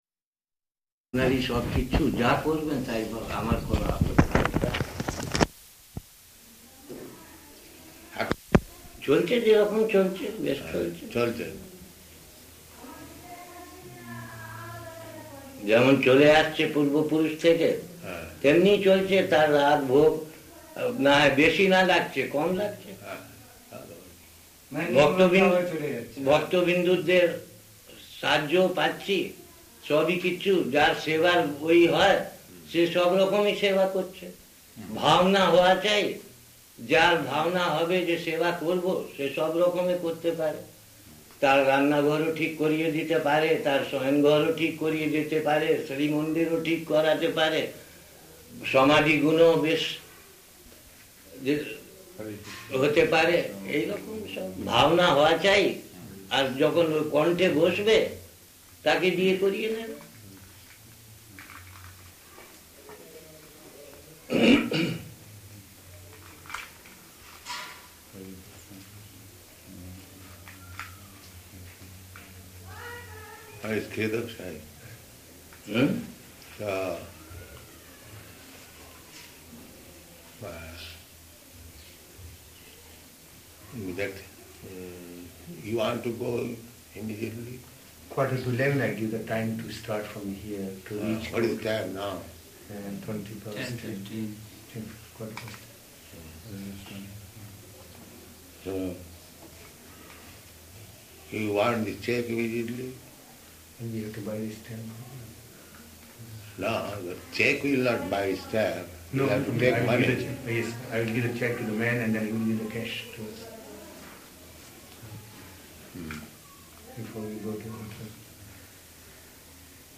Room Conversation
-- Type: Conversation Dated: March 11th 1972 Location: Mathura Audio file
[much honking of horn] Prabhupāda: To ride on a car in Vṛndāvana.